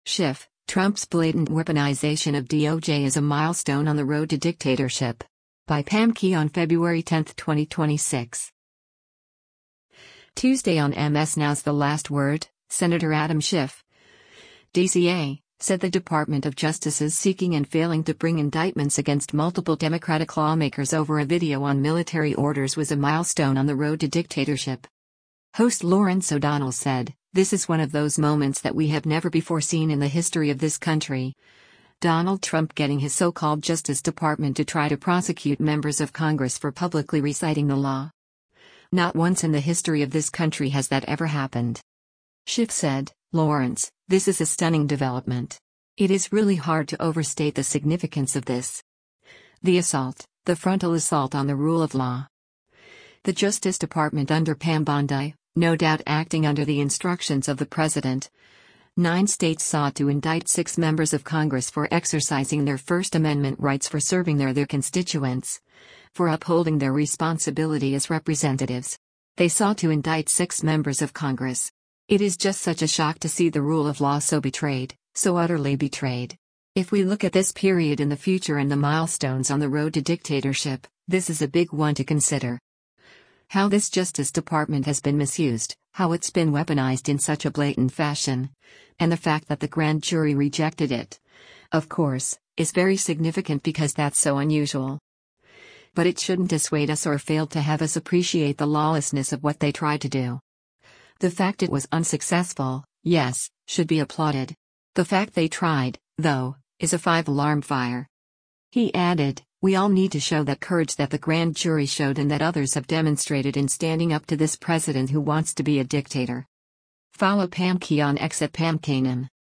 Tuesday on MS NOW’s “The Last Word,” Sen. Adam Schiff (D-CA) said the Department of Justice’s seeking and failing to bring indictments against multiple Democratic lawmakers over a video on military orders was a milestone on the “road to dictatorship.”